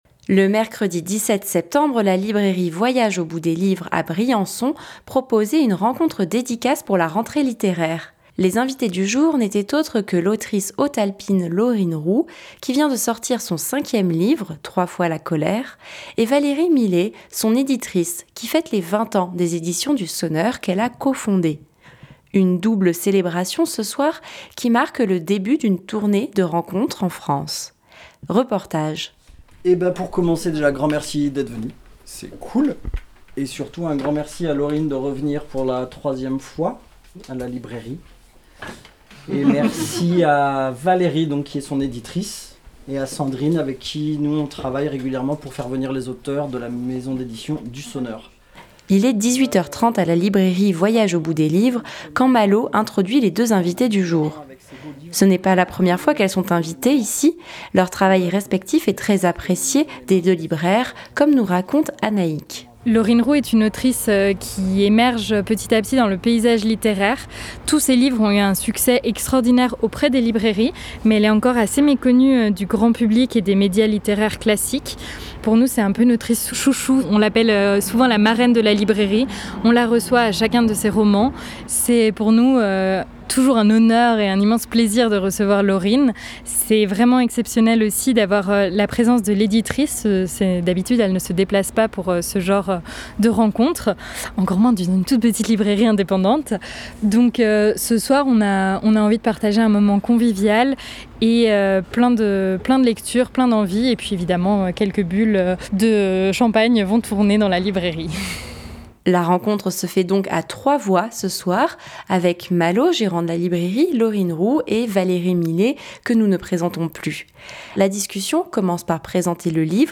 Mercredi 17 septembre, la librairie Voyage au bout des livres à Briançon proposait une rencontre dédicace pour la rentrée littéraire.